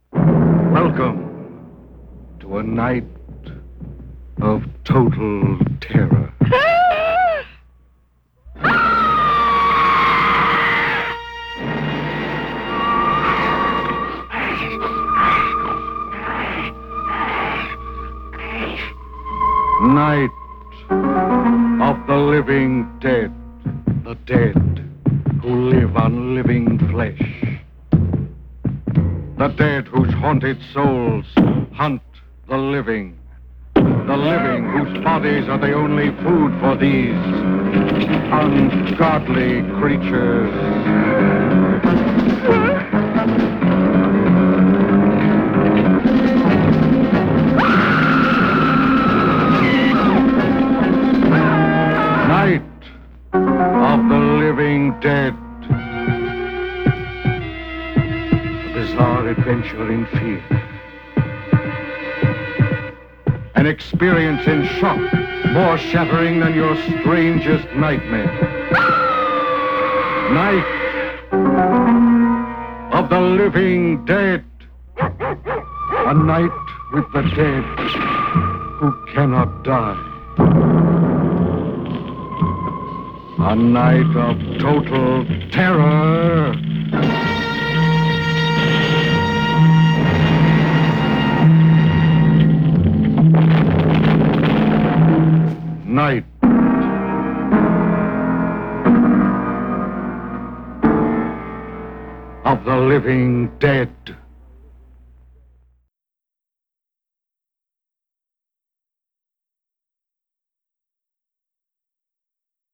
予告編